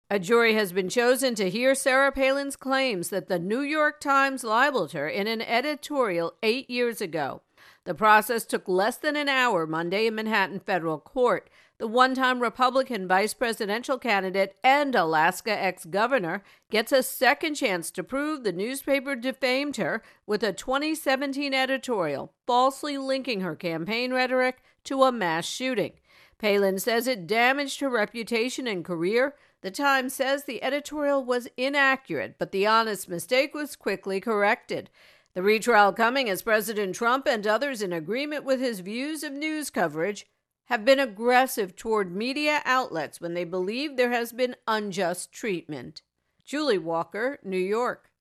reports on the start of the Sarah Palin New York Times court case.